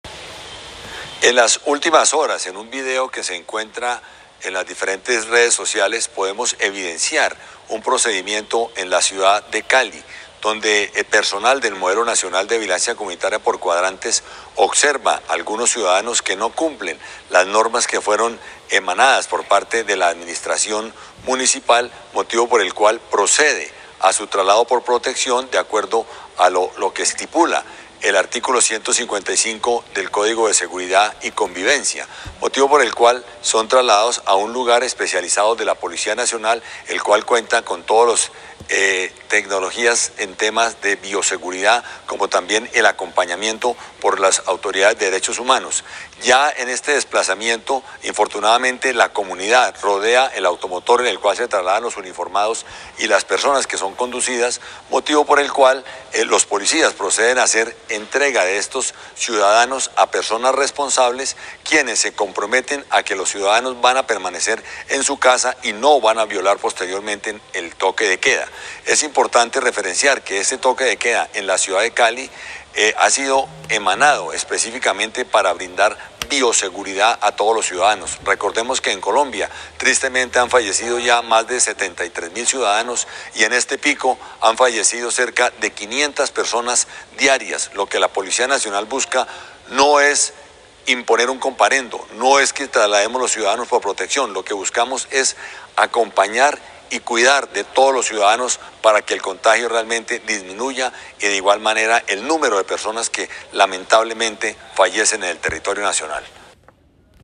Declaraciones del general Carlos Rodríguez, director de Seguridad Ciudadana de la Policía Nacional.